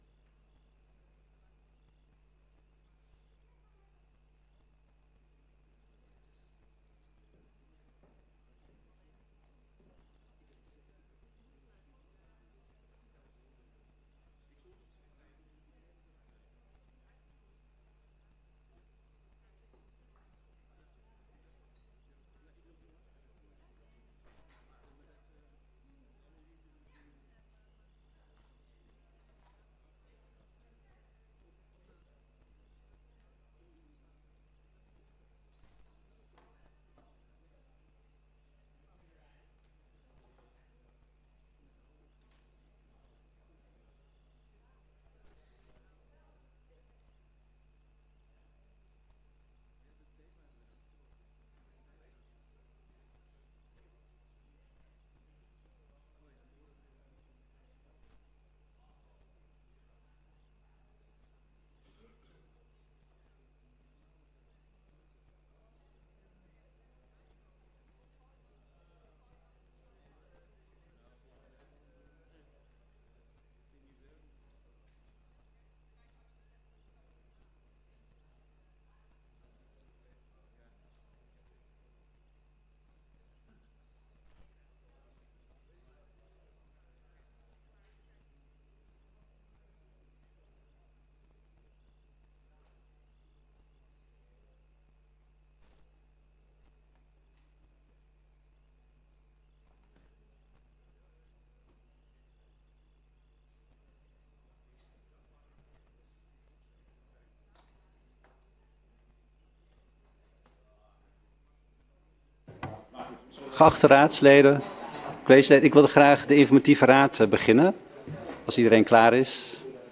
Informatieve raadsvergadering 26 september 2024 20:00:00, Gemeente Diemen
Locatie: Raadzaal